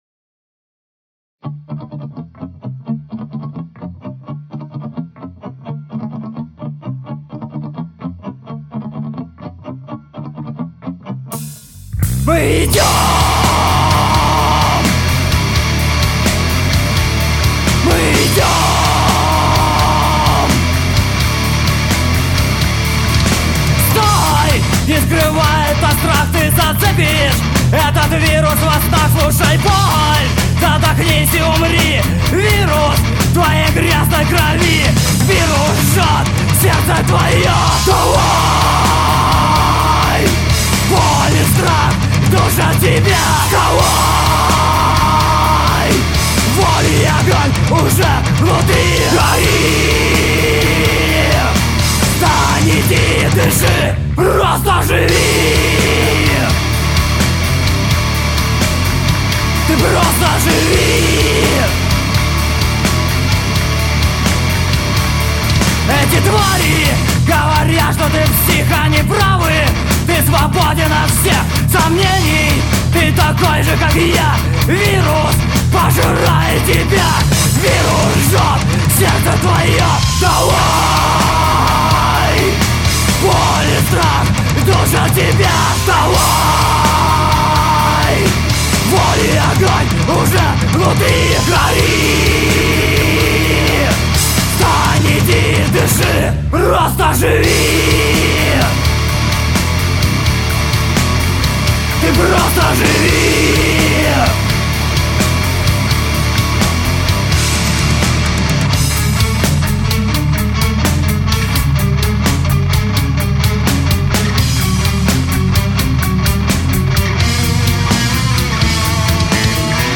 Творчество группы несколько утяжелилось